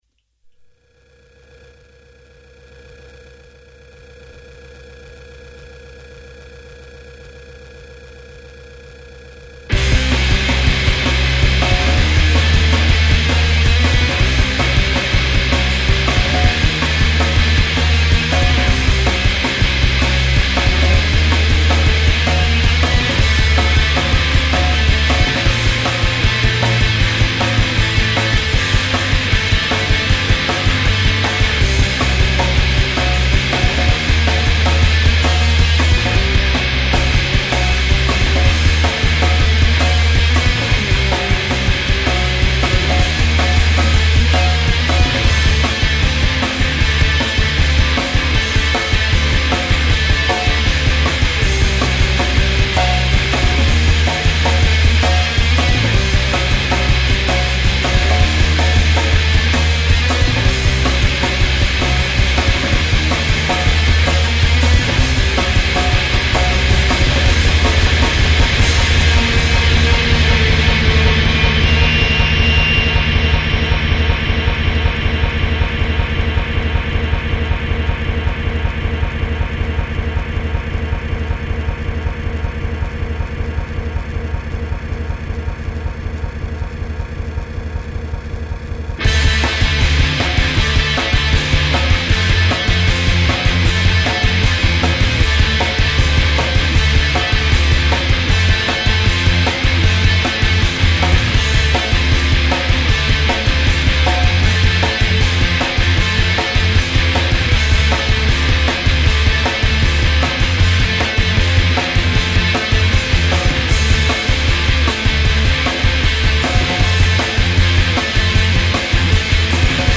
avangarde-rock
Voices, Guitars, Piano, Tapes
Basses, Flute, Metal, Tapes
Drums,Trumpet,Table, Percussion
Ipnotici, melodici e volutamente estremi